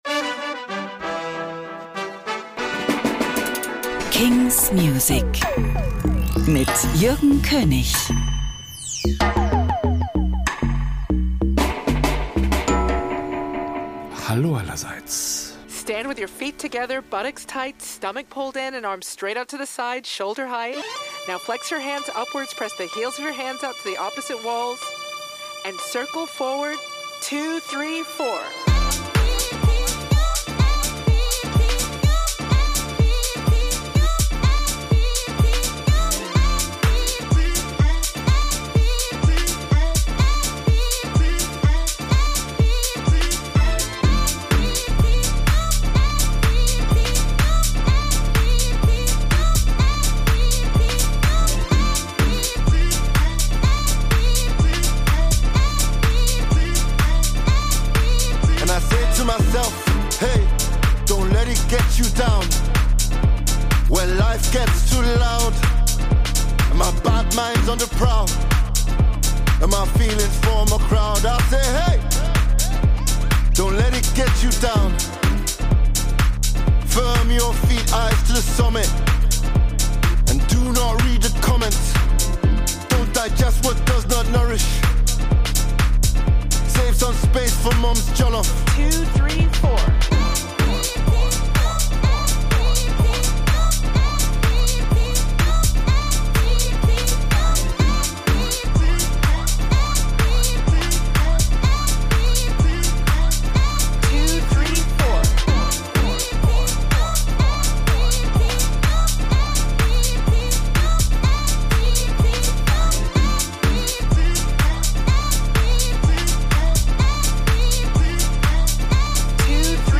great, new indie & alternative releases